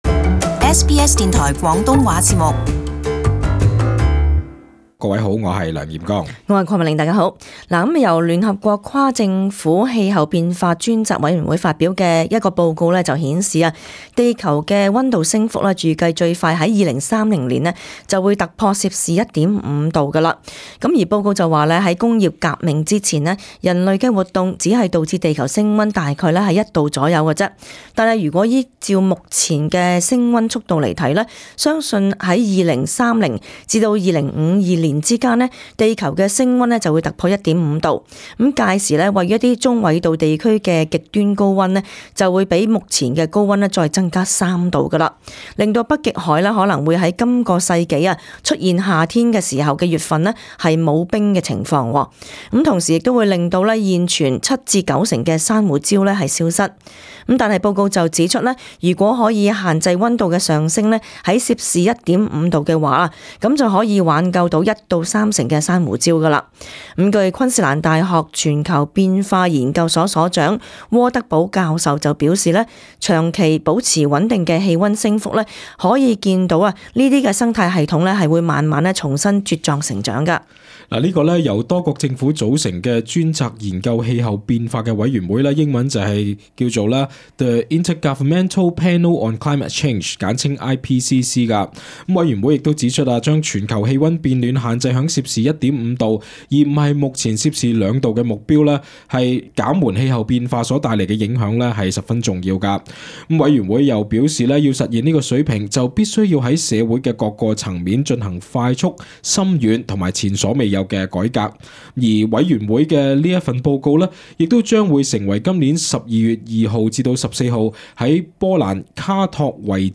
【时事报导】联合国吁再减碳排放 莫理逊：巴黎协定目标已足够